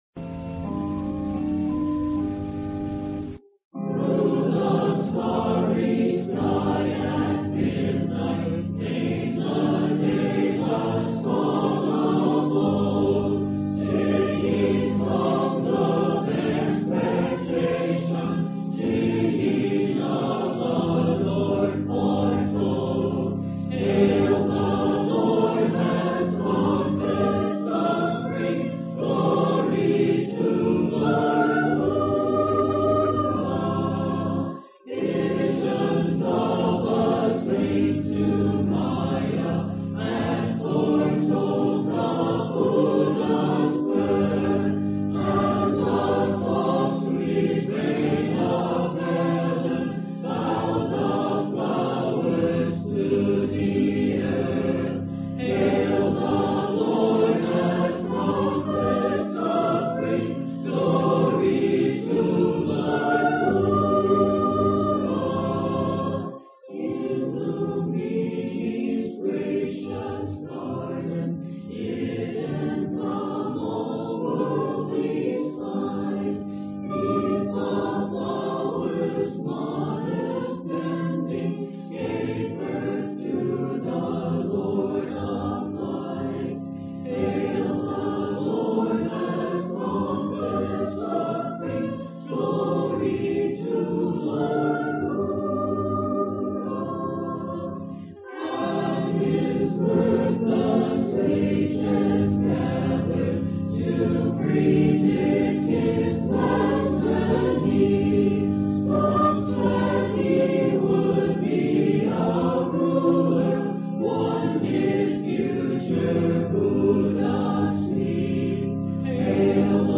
Today, May 17th, 2020, with the “Shelter in Place” still in place, we celebrated Wesak (Vesak ~ Festival for Buddha’s Birthday) at the Priory with forty-five members and friends joining via Zoom.
and we chanted the Wesak songs for this special occasion. The offertory sang by our monk expressed our deep gratitude for the Buddha’s great teachings: